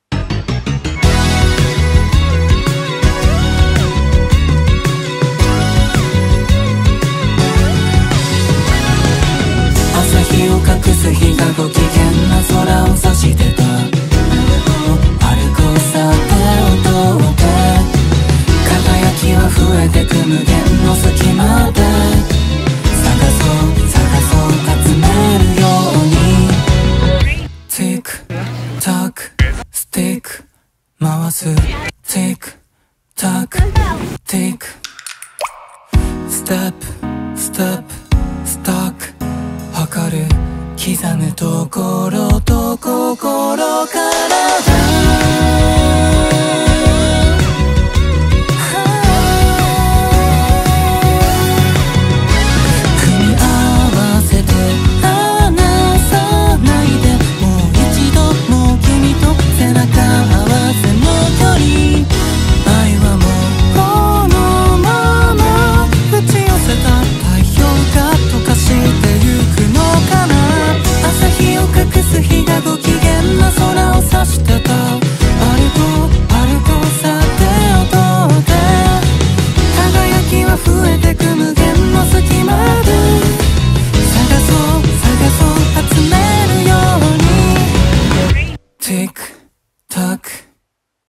BPM83
Audio QualityPerfect (High Quality)
City pop my beloved